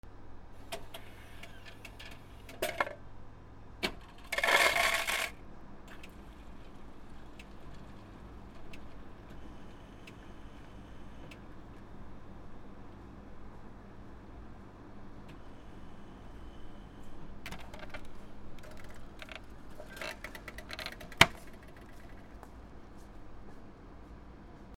ドリンクサーバー 氷 炭酸飲料
メロンソーダ R26XY